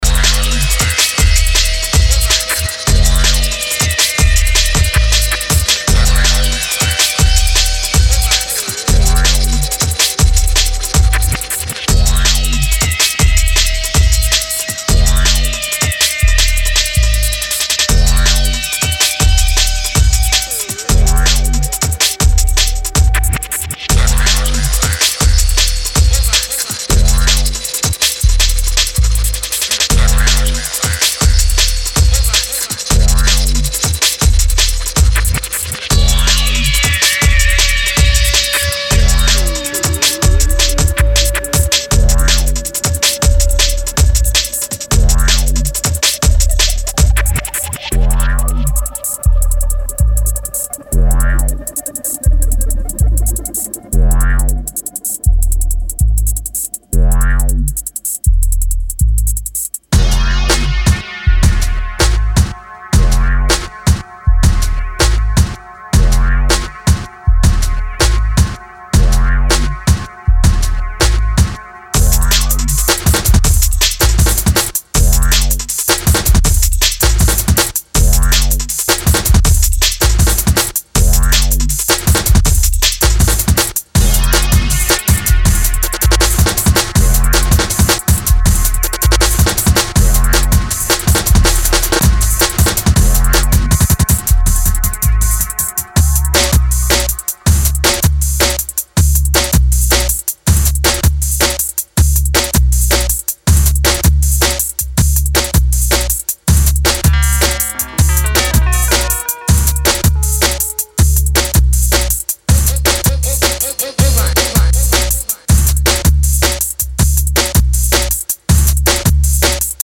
vocals
Disco Italo